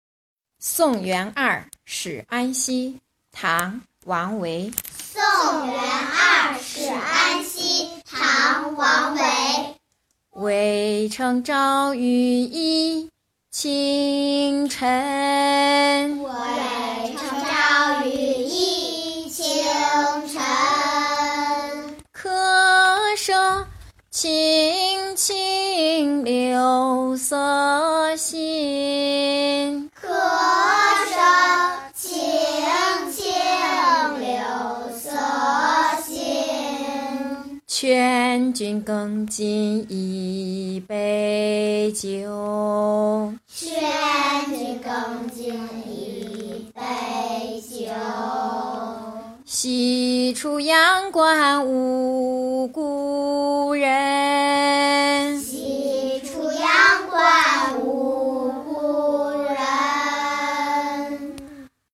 《送元二使安西》学吟诵